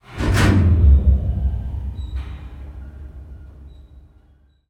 amb_elevator_start.ogg